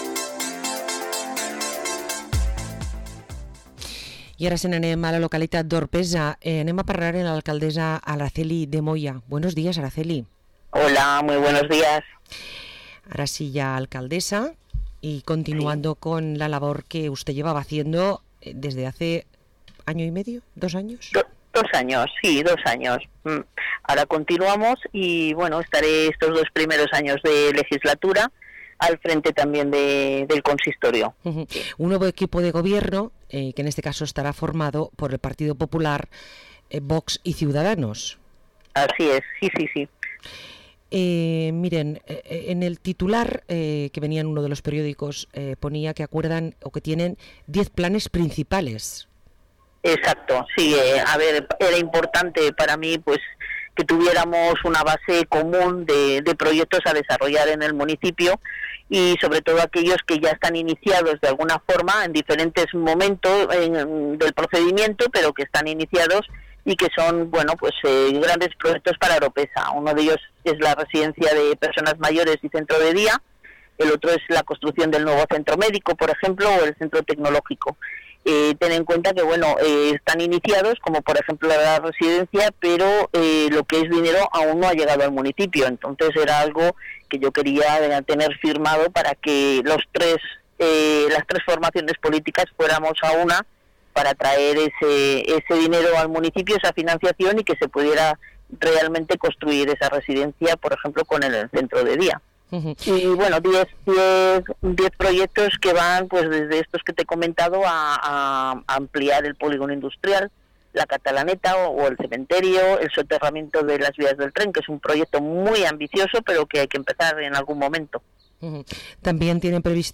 Entrevista a l’alcaldessa d’Oropesa, Araceli de Moya